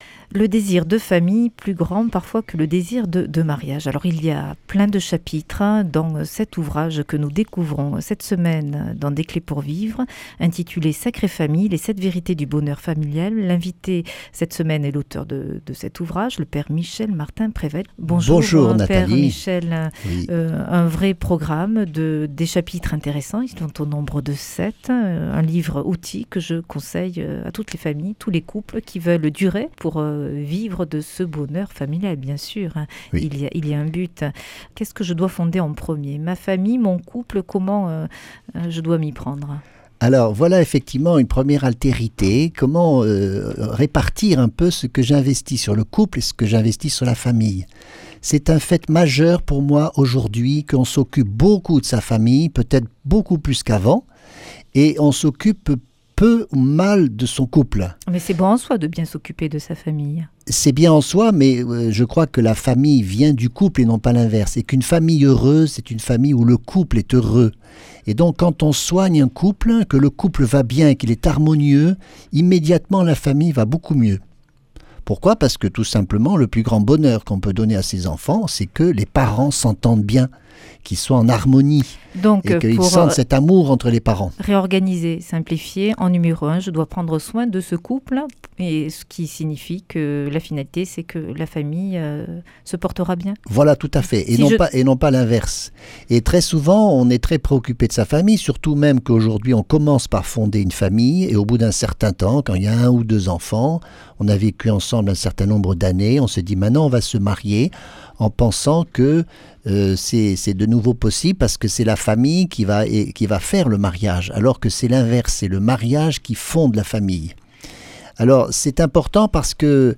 Une émission présentée par